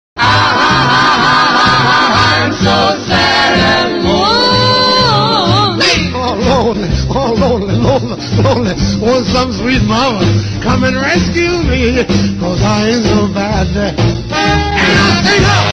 Yoda sings
yoda-singing.mp3